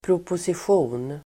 Uttal: [proposisj'o:n]